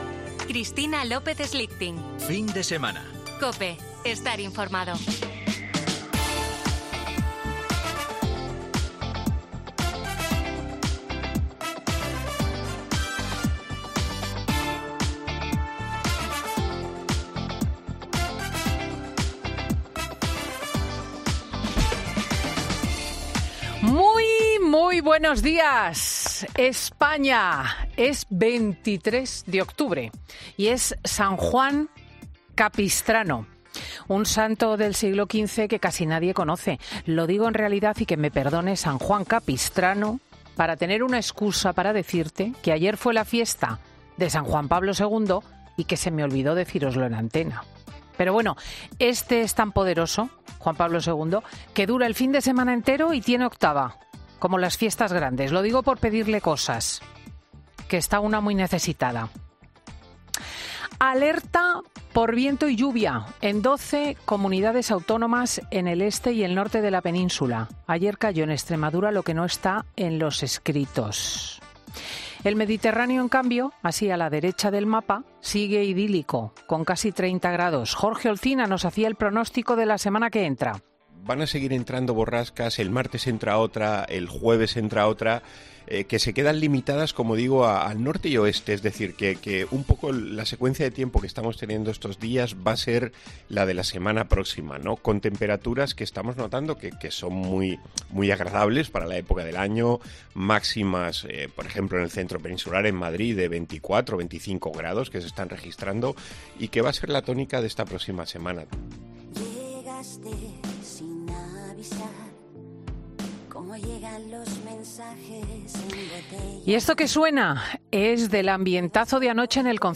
Escucha el monólogo de Cristina López Schlichting en el programa Fin de Semana de este domingo